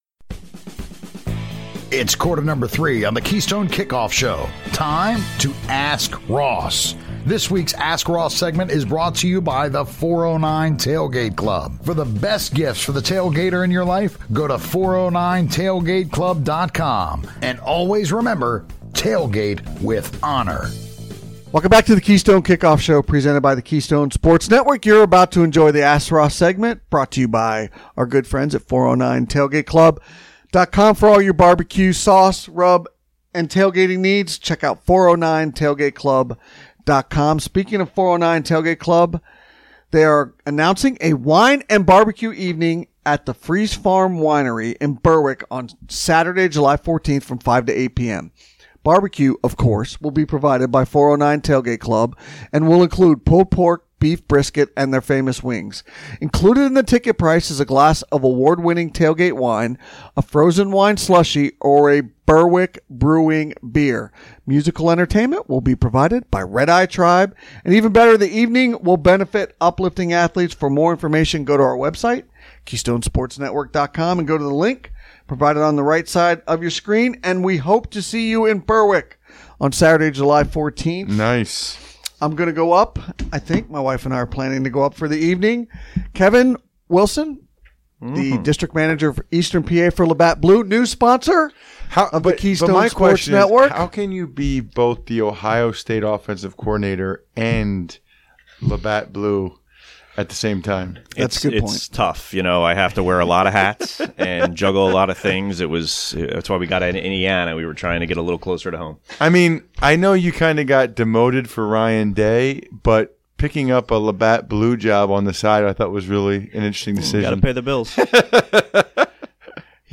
joins us in studio